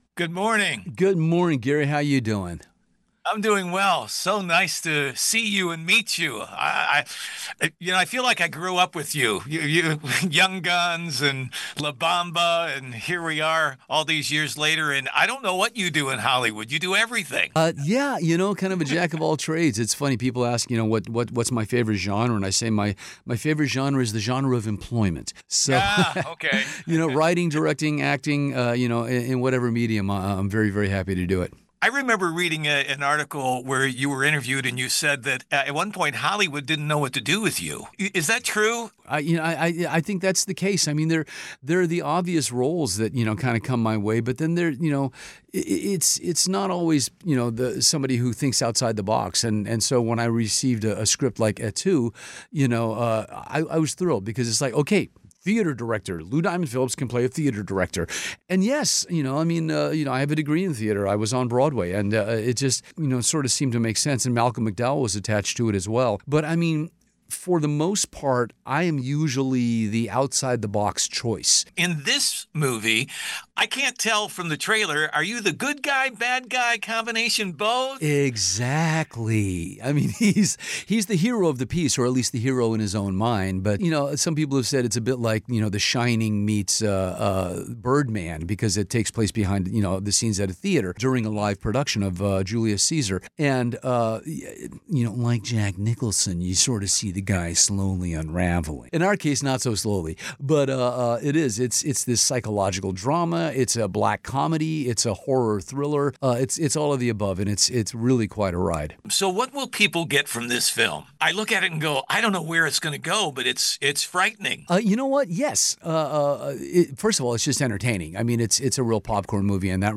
loudiamond_mixdownradioedit.mp3